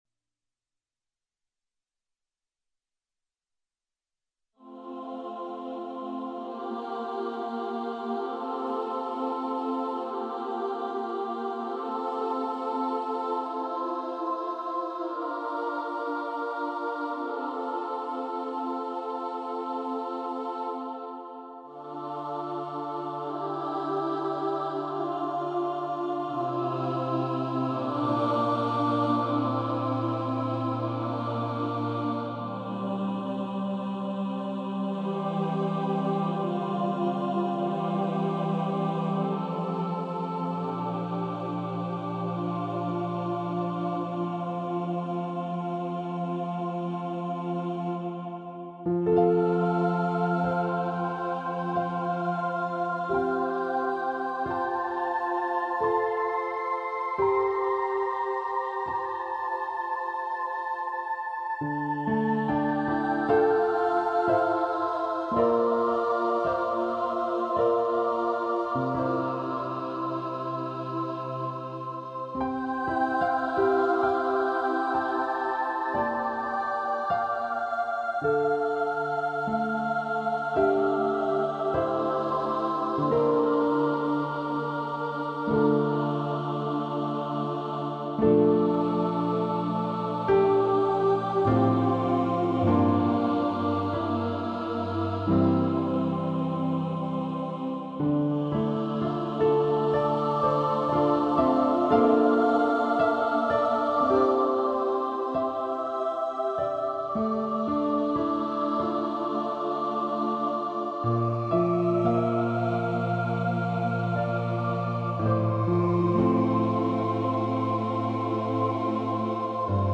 The first project is simply instrumental Catholic/Christian standards as music for meditation to use during Lent and Holy Week.